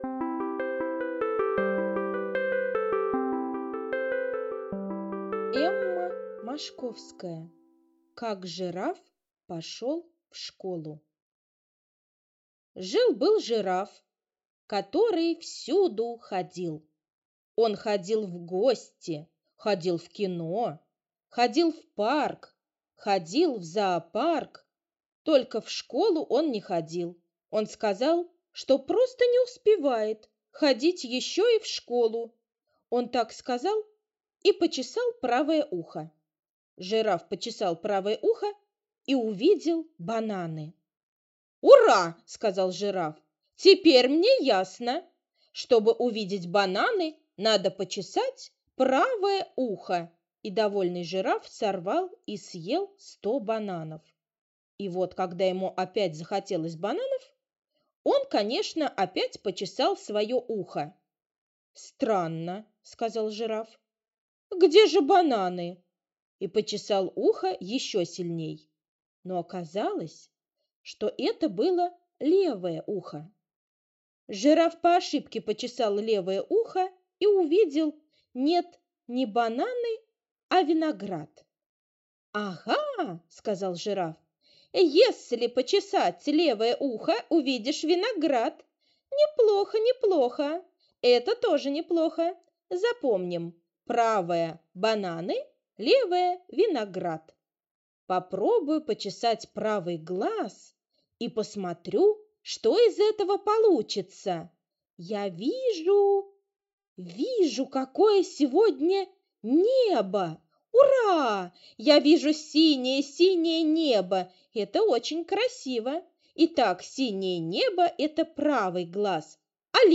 Аудиосказка «Как жираф пошел в школу»